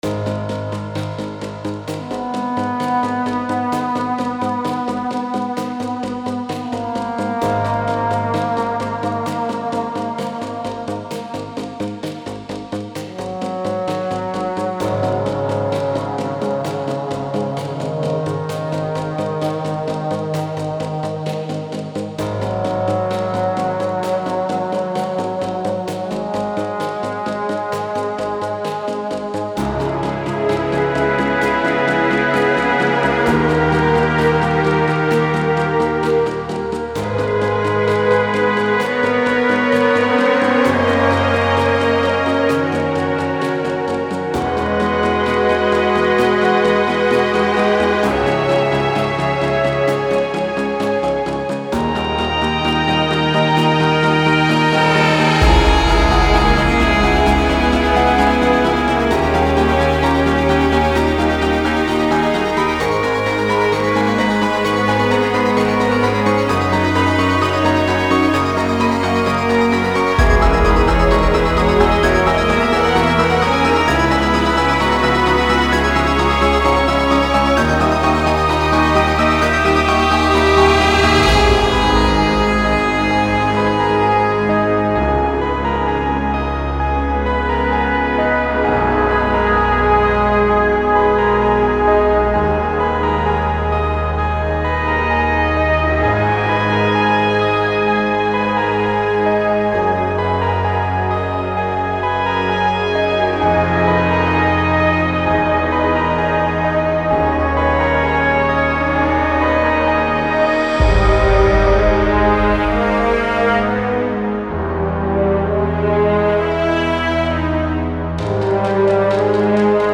Electronic + orchestral music